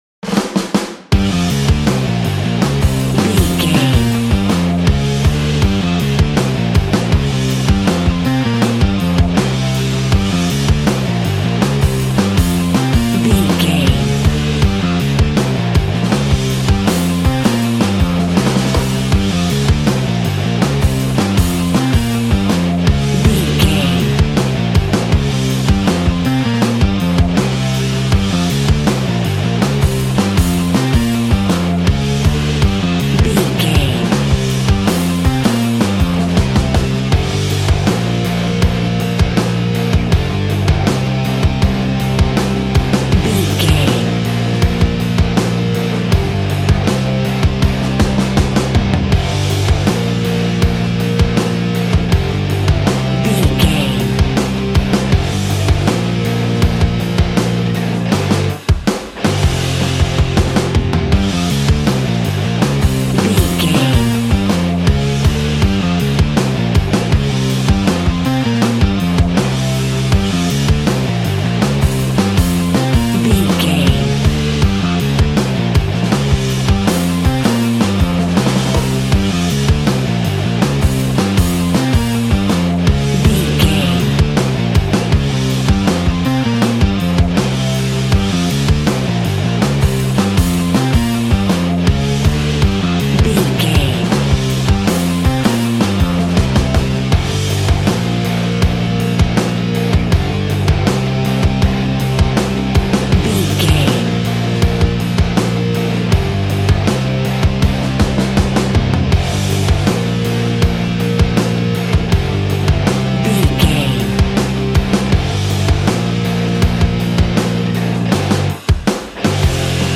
Aeolian/Minor
angry
heavy
powerful
aggressive
electric guitar
drums
bass guitar